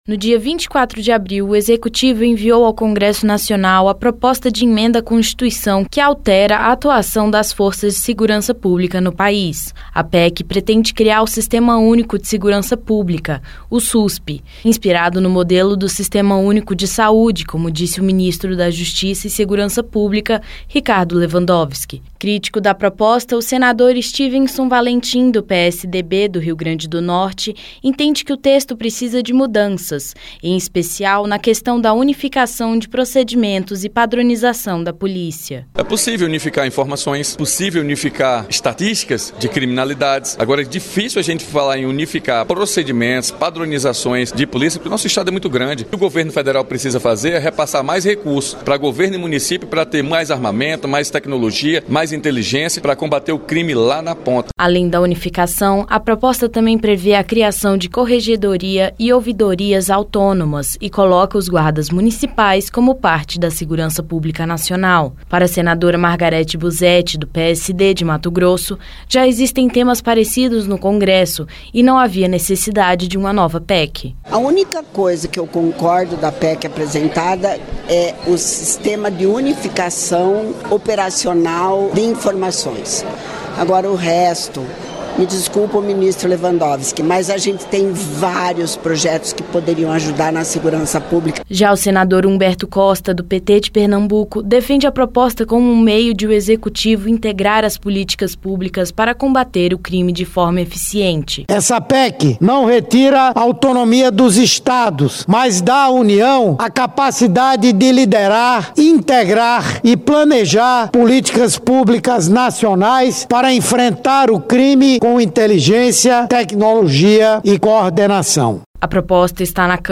Repercussão